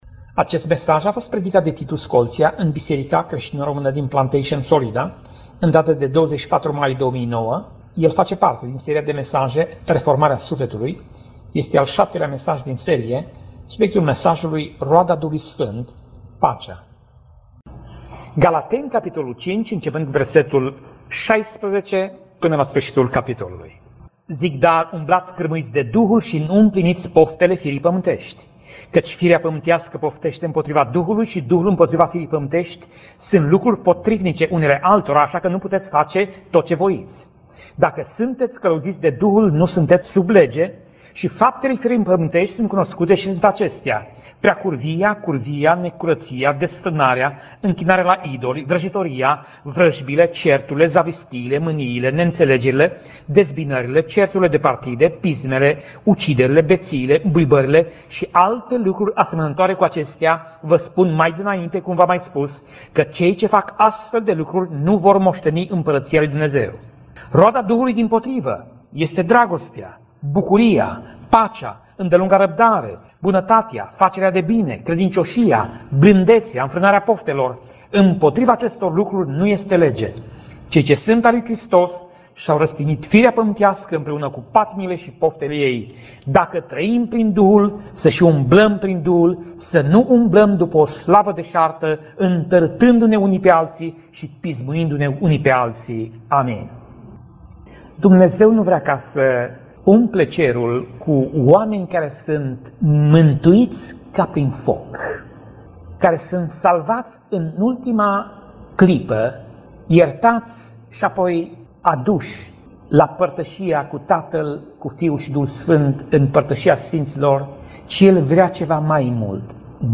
Pasaj Biblie: Galateni 5:16 - Galateni 5:26 Tip Mesaj: Predica